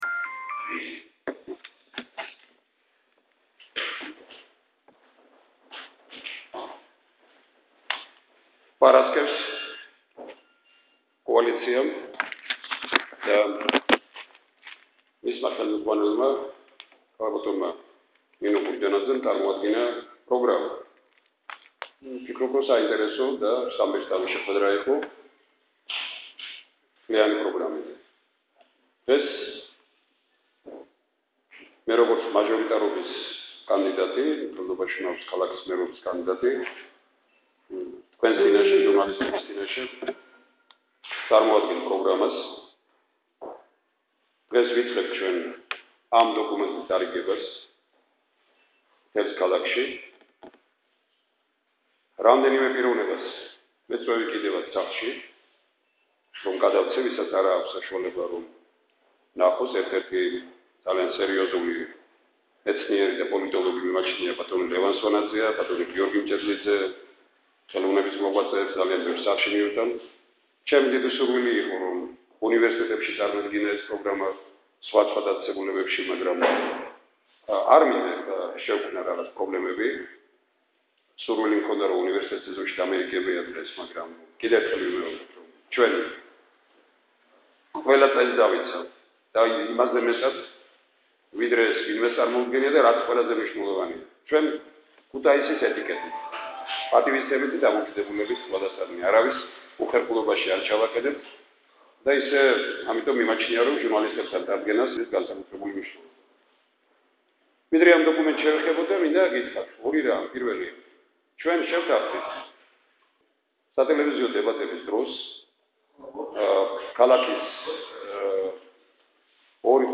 (აუდიოჩანაწერი) თემურ შაშიაშვილის ბრიფინგი: მოვდივარ თქვენი მსახური და არა თქვენი უფროსი! (09.06.2014წ)